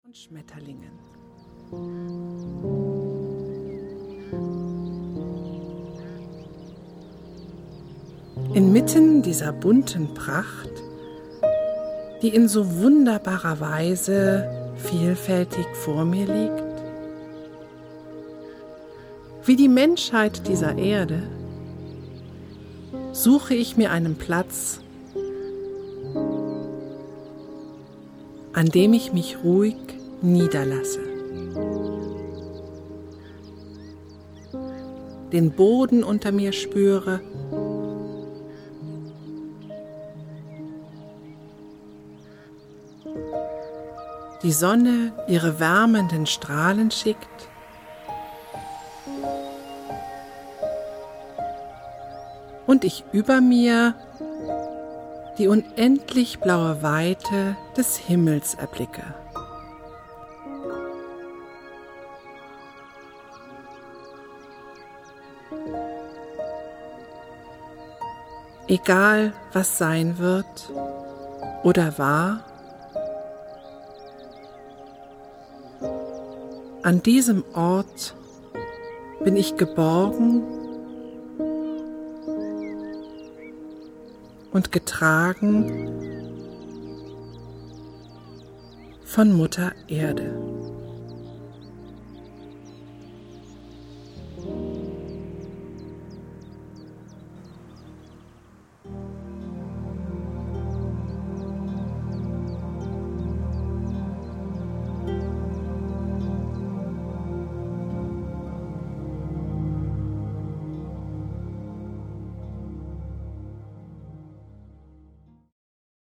Eine innere Reise zur Ruhe mit entspannenden und bewußtseinserweiternden Texten, untermalt mit Naturgeräuschen und weichsanften Klängen.